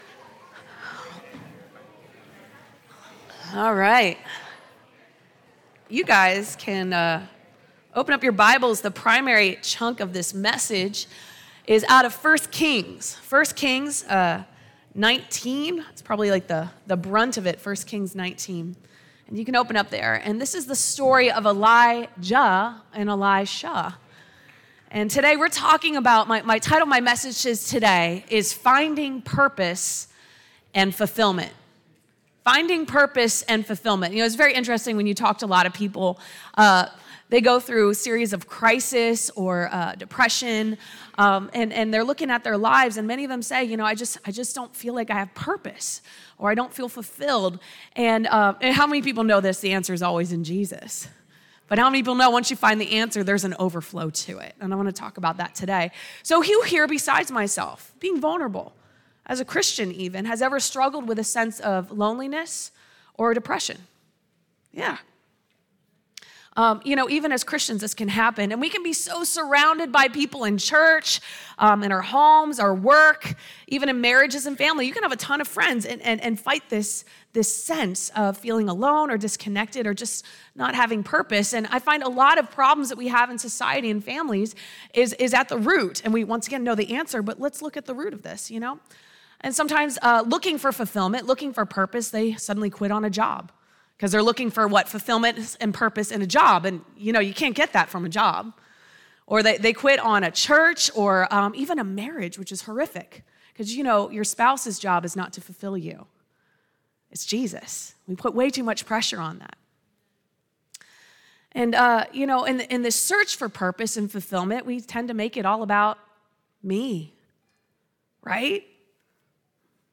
Sunday AM Service